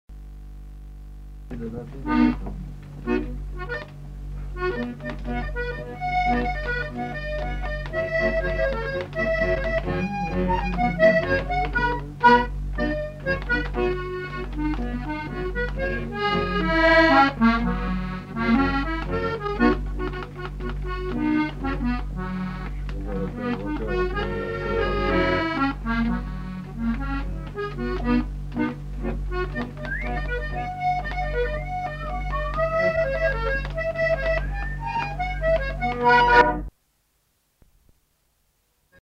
Genre : morceau instrumental
Instrument de musique : accordéon diatonique
Danse : quadrille
Ecouter-voir : archives sonores en ligne